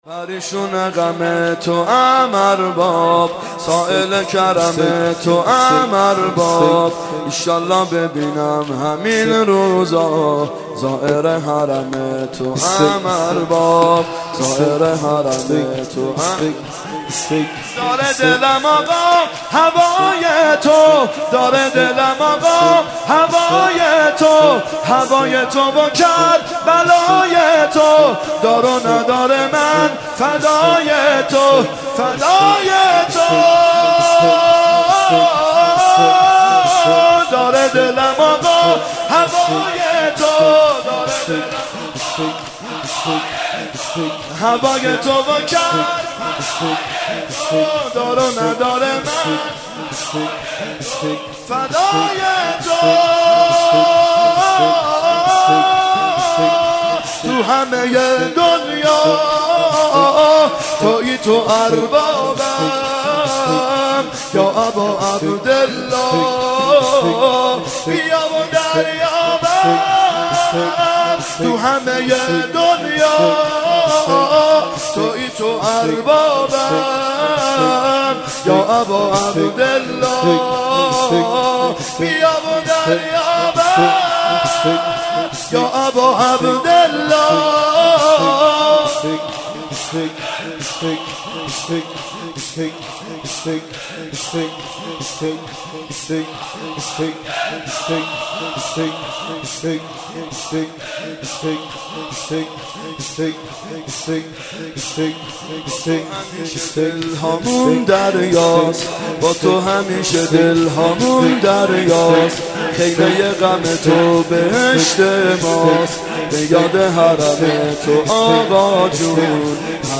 محرم 92 شب سوم شور (گریشون غم توام ارباب
محرم 92 ( هیأت یامهدی عج)